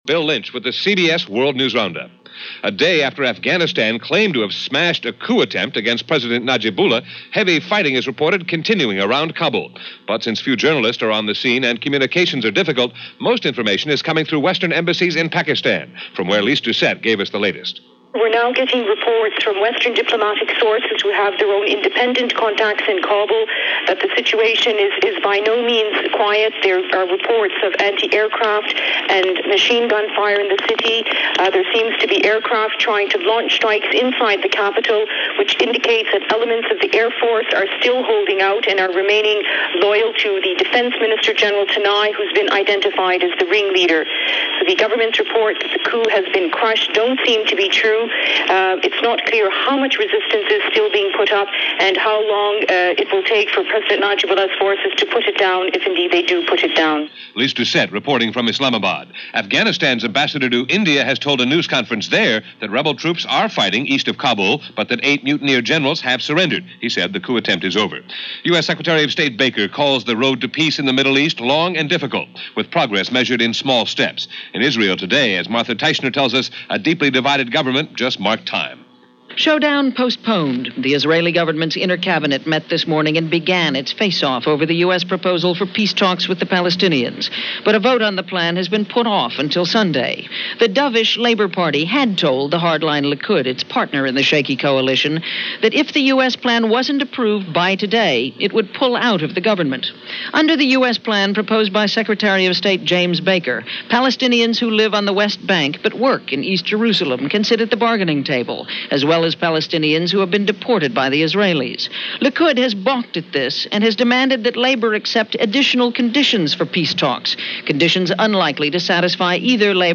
March 8, 1990 – CBS World News Roundup – Gordon Skene Sound Collection –